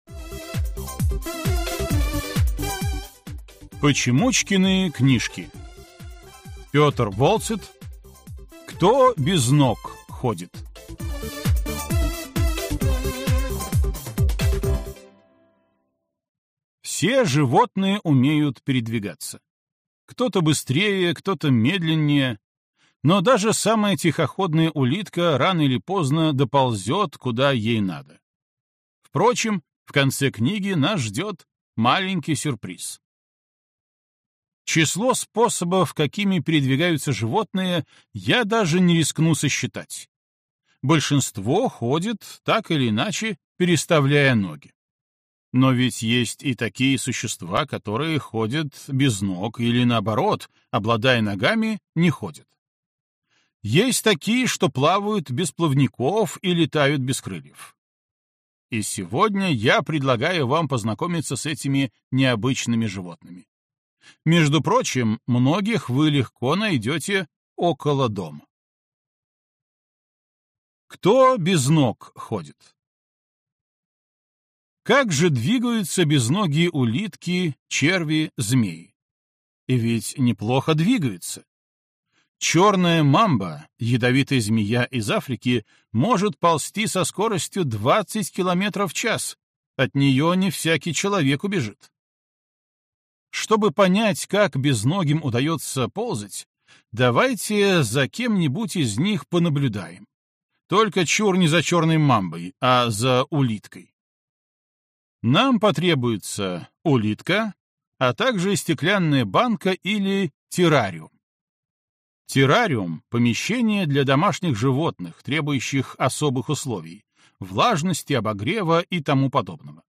Аудиокнига Кто без ног ходит?